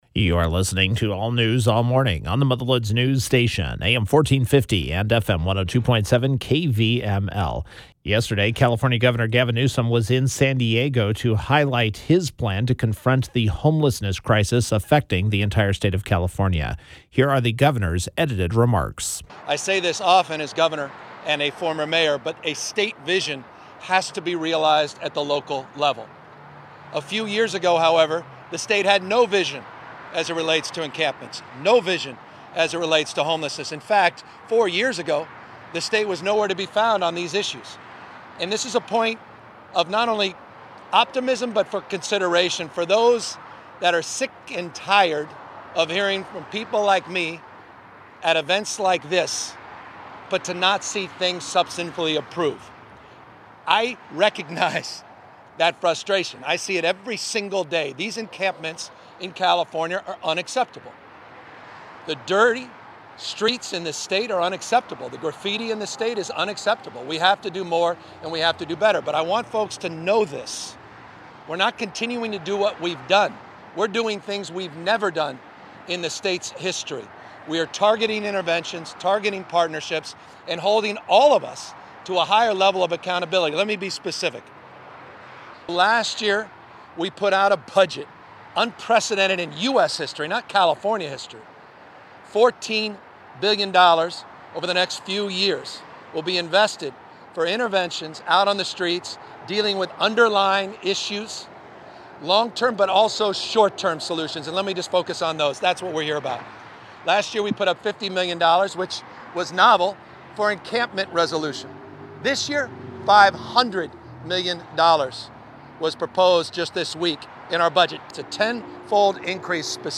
Following the unveiling of his California Blueprint, Governor Gavin Newsom visited a homeless encampment in San Diego to highlight his proposed additional $2 billion package – for a total of $14 billion – to the state’s multi-year plan to confront the homelessness crisis, which will create 55,000 new housing units and treatment slots when fully implemented.